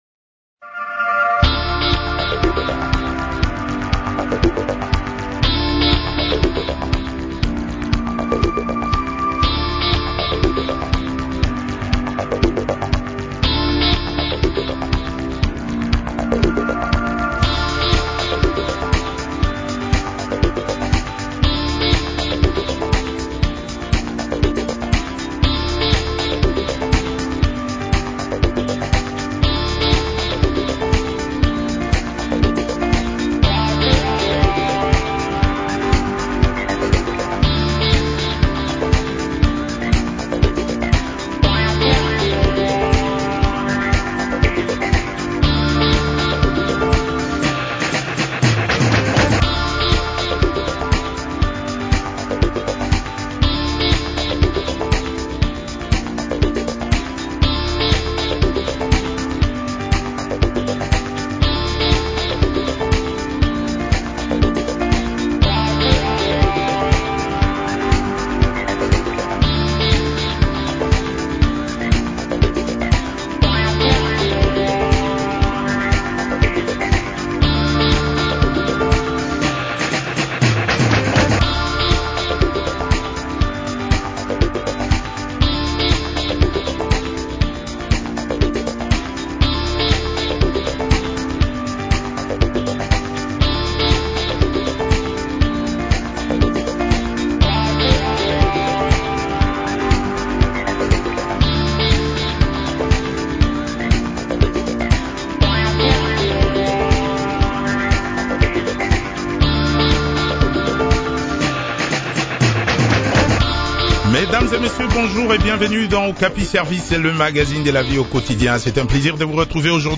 avocat au barreau du Kongo Central a également participé à cet entretien.